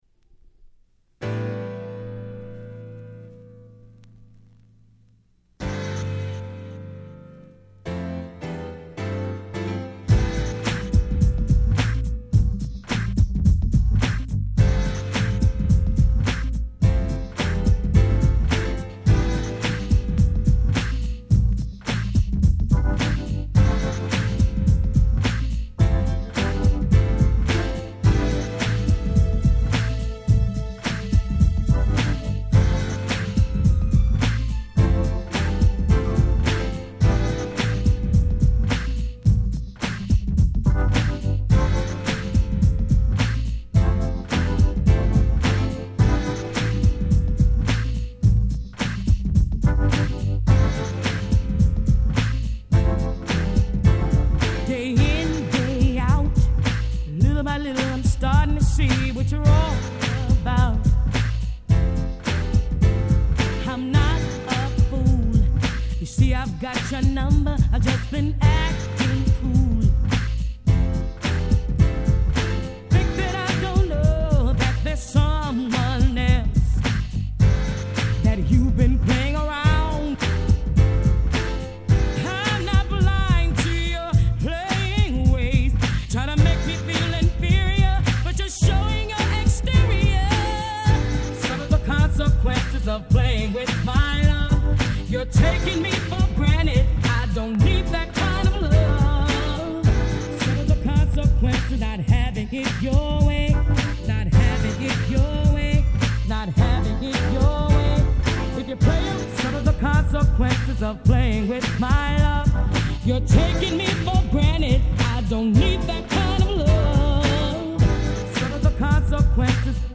【Media】Vinyl 12'' Single
95' Nice UK R&B !!
疾走感もバッチリ！